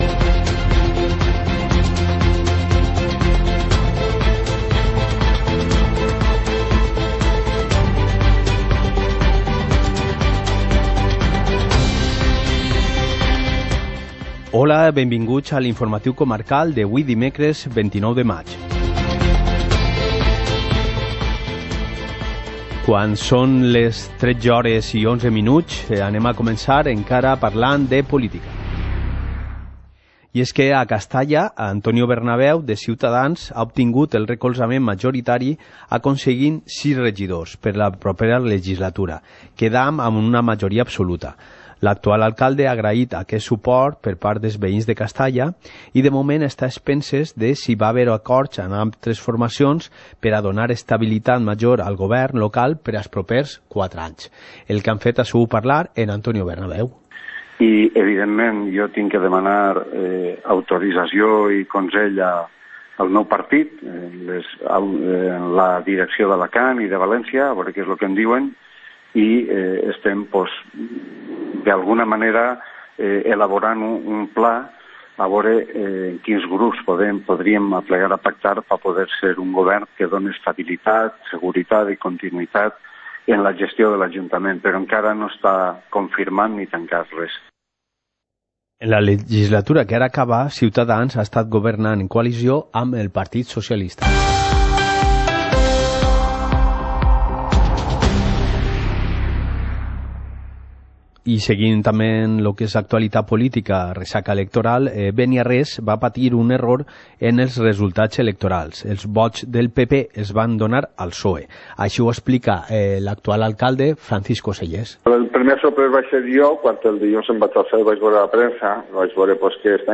Informativo comarcal - miércoles, 29 de mayo de 2019